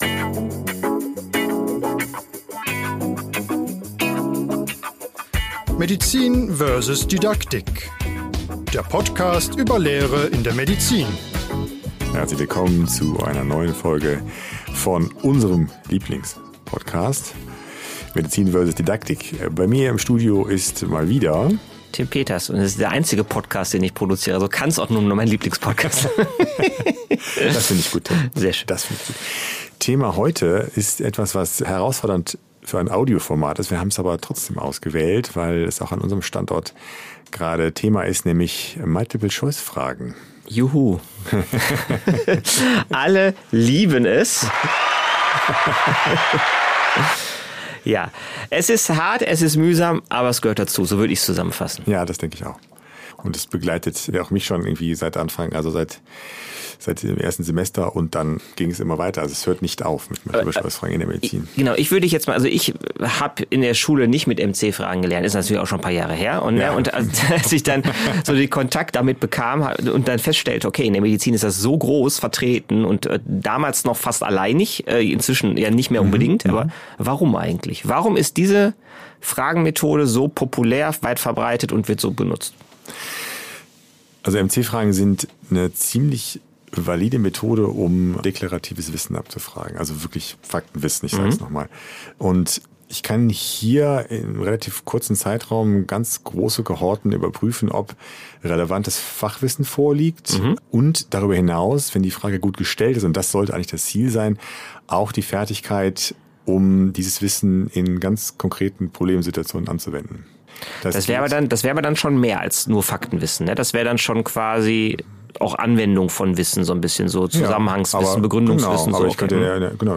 Es gibt vielleicht spannendere Themen, aber immerhin sind sie wichtig, kommen oft vor und überhaupt. Und gelacht wird irgendwo in der Folge auch … bestimmt … vielleicht …